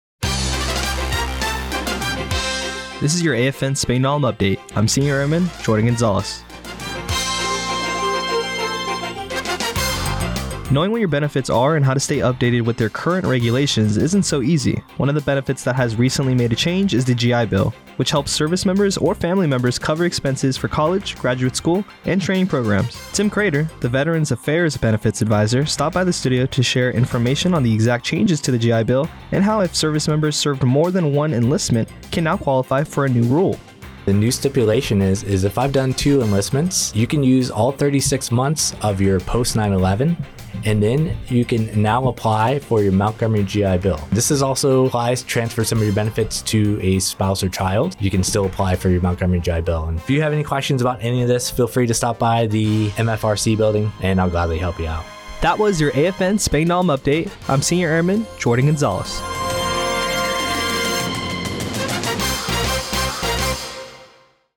Radio news on 10-17-24 on Gi Bill.